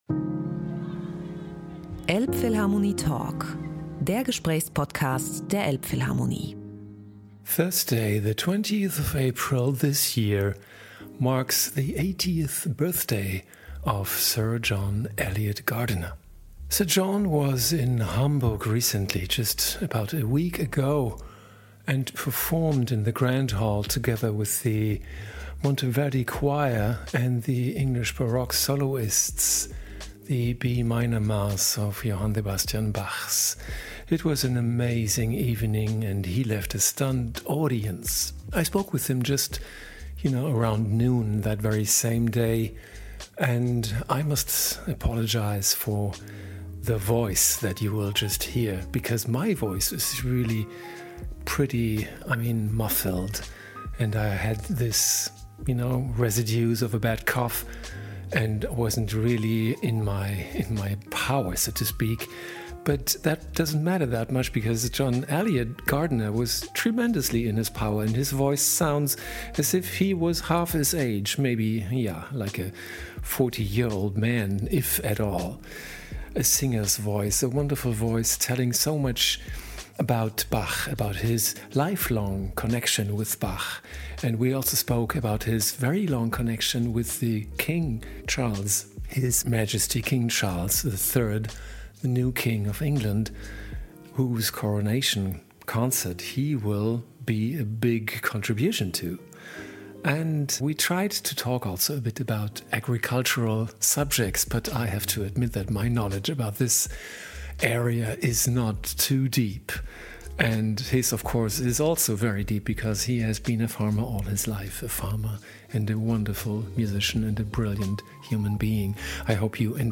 Wenige Stunden vor dem Konzert nahm Sir John sich die Zeit für einen sehr munteren »Elbphilharmonie Talk«. Da Sir John die Ehre zuteil wird, bei den Krönungsfeierlichkeiten für Englands König Charles III. am 6. Mai 2023 einiges an Musik beizusteuern, kommt auch sein Verhältnis zum neuen Monarchen zur Sprache, mit dem ihn neben der Liebe zur Musik auch die Sorge um die Erde und die Verantwortung für eine nachhaltige Landwirtschaft verbindet.
Aussehen tut er wie 60, und er klingt wie 40 – dynamisch, voller Begeisterung und Tatendrang.